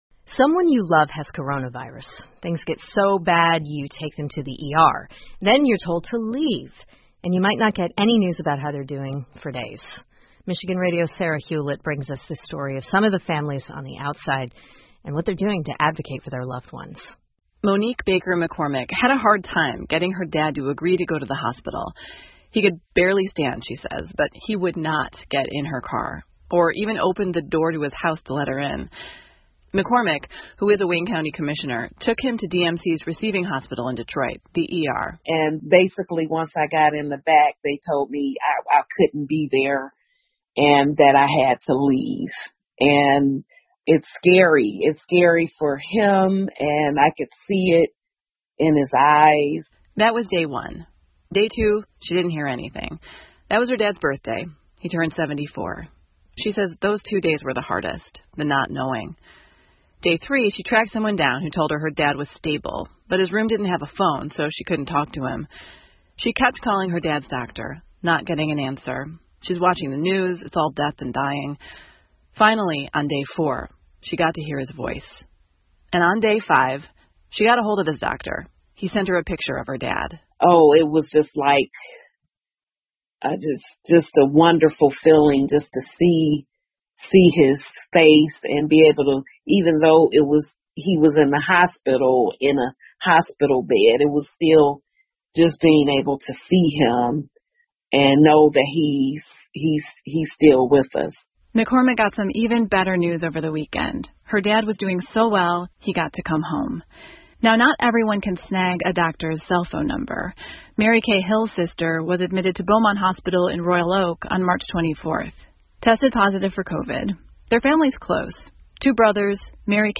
密歇根新闻广播 为患有COVID-19的亲人辩护 听力文件下载—在线英语听力室